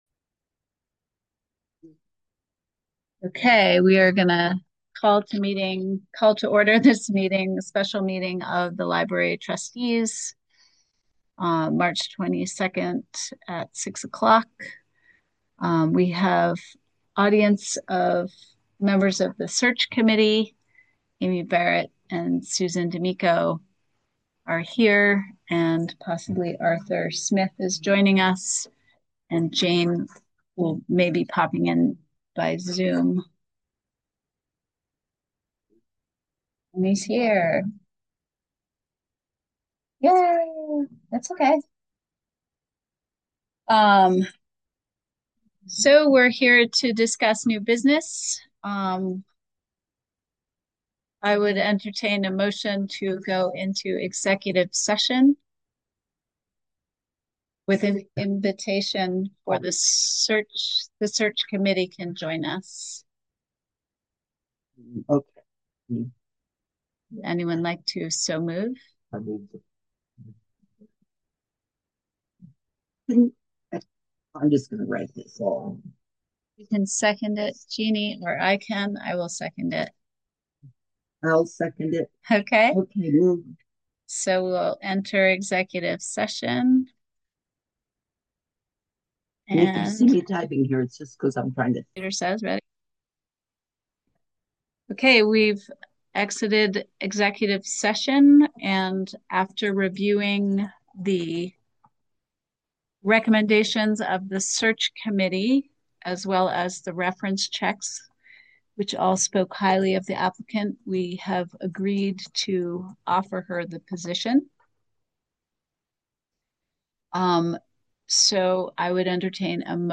Roxbury Free Library Board of Trustee’s Meeting March 22 2026 at 18:00
Physical Meeting location: Roxbury Free Library, 1491 Roxbury Rd., Roxbury VT.